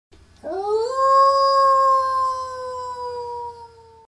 wolf_howl.mp3